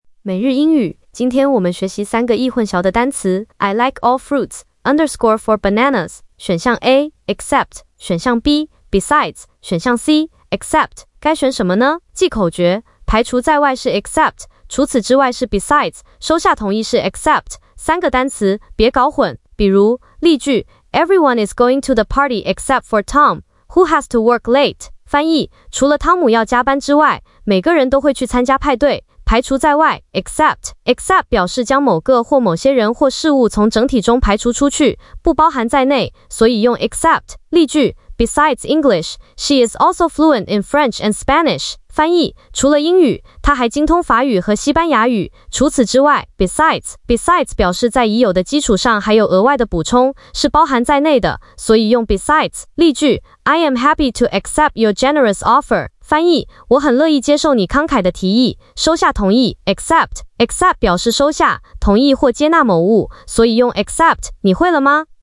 🎧 语音讲解